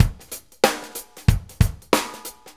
Shuffle Loop 28-01.wav